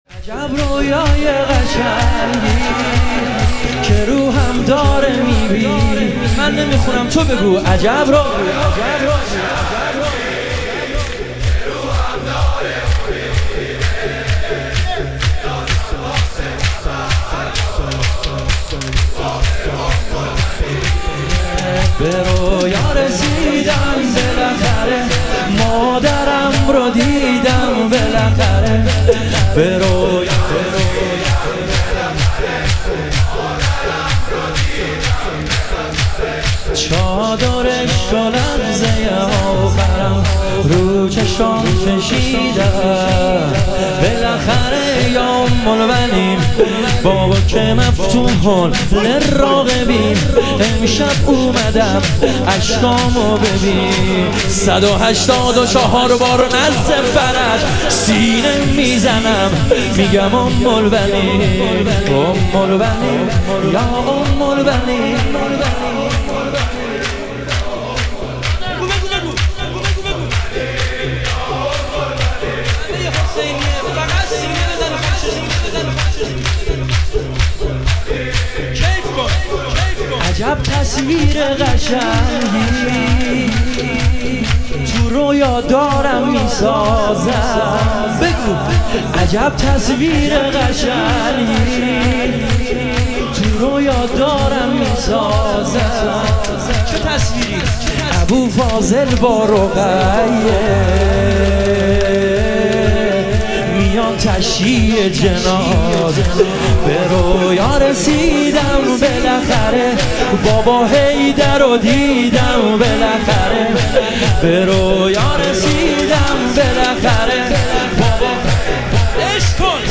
شهادت امام کاظم علیه السلام - شور - 13 - 1403